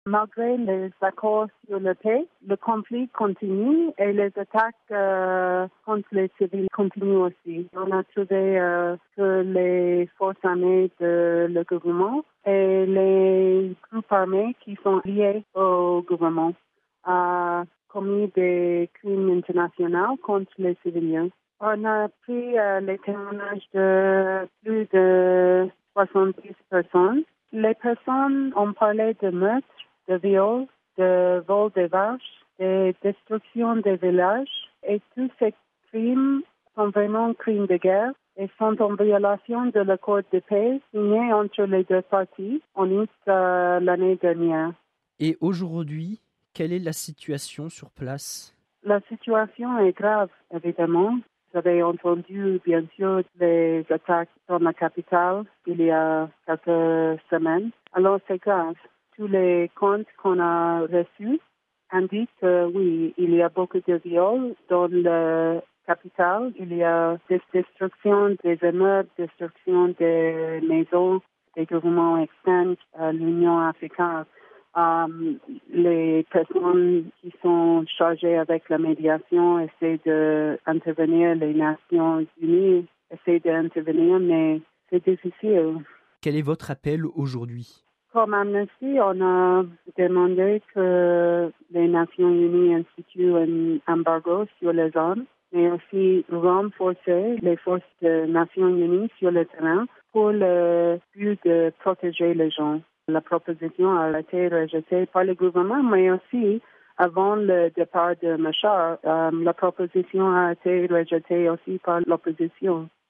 (RV) Entretien - Homicides, viols, enlèvements, incendies : les violences continuent au Soudan du Sud, et ce malgré l’accord de paix signé il y a près d’un an.